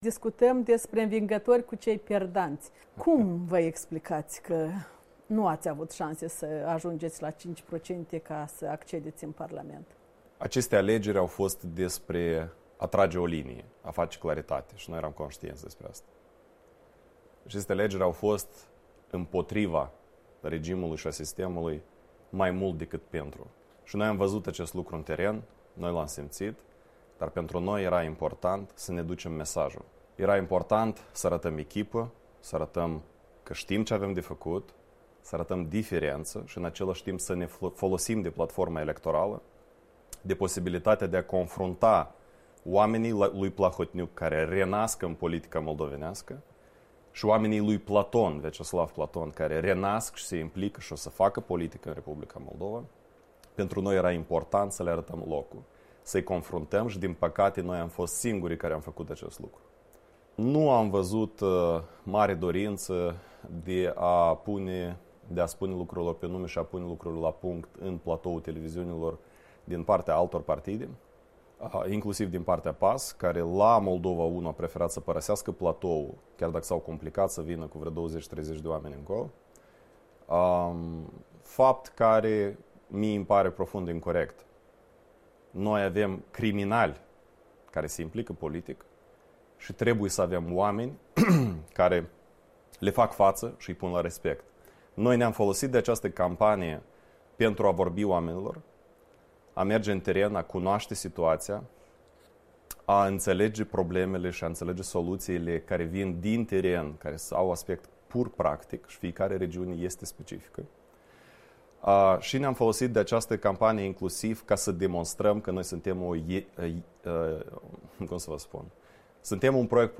în studioul Europei Libere
Interviu